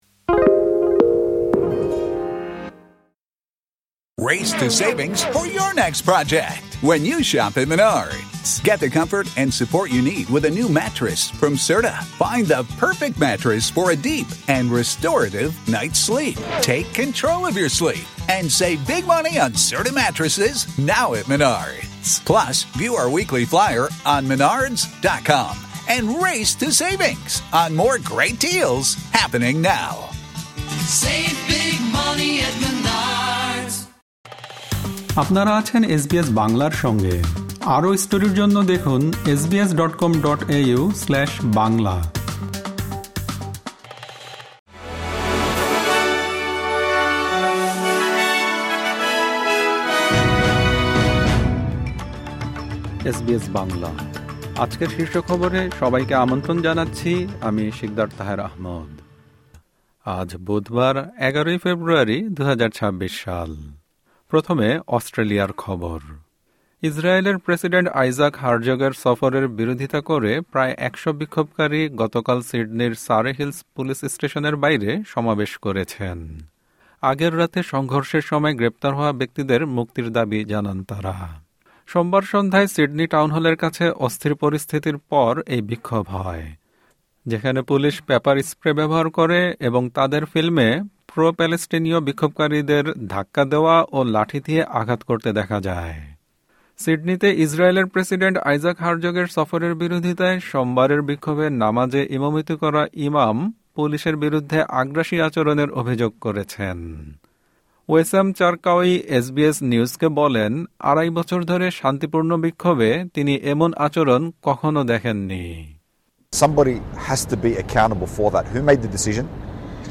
এসবিএস বাংলা শীর্ষ খবর: সিডনিতে ফিলিস্তিনপন্থী সমাবেশে গ্রেপ্তার হওয়াদের মুক্তির দাবি জানিয়েছেন বিক্ষোভকারীরা